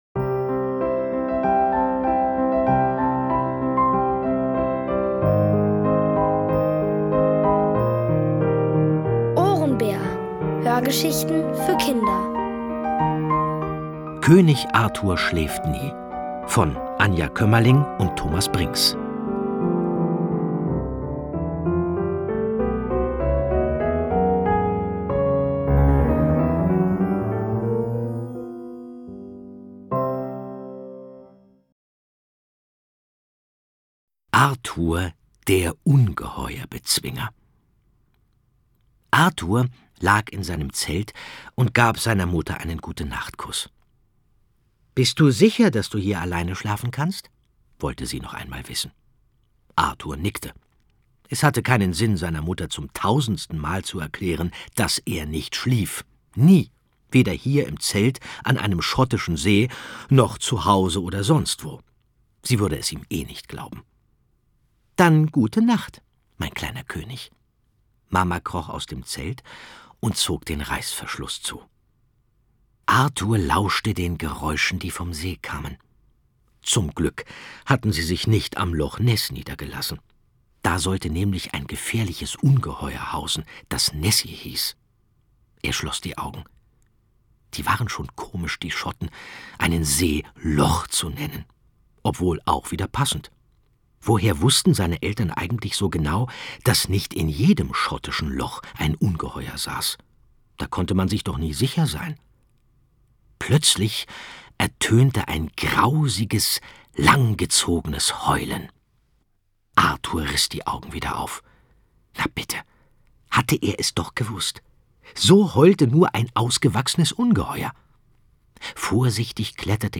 Von Autoren extra für die Reihe geschrieben und von bekannten Schauspielern gelesen.
OHRENBÄR-Hörgeschichte: König Arthur schläft nie, 2 (Folge 6 von 7)
Es liest: Andreas Fröhlich.